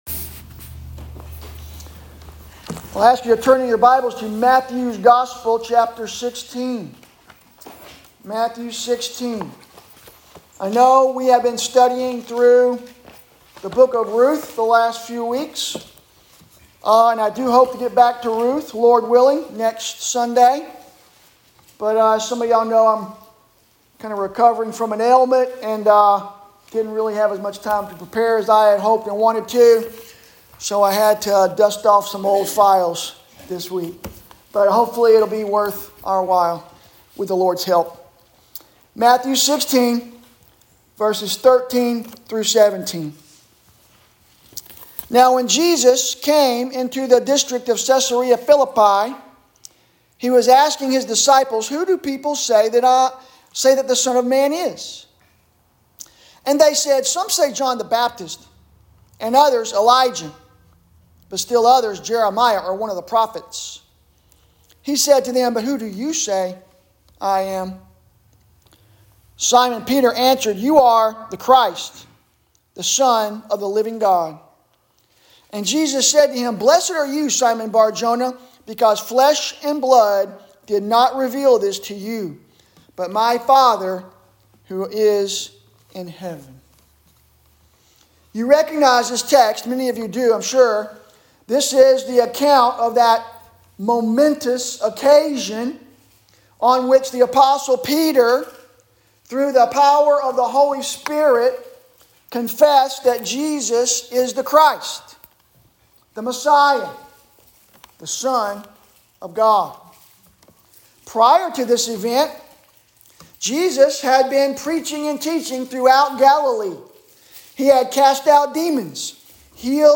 Sermons – First Baptist Church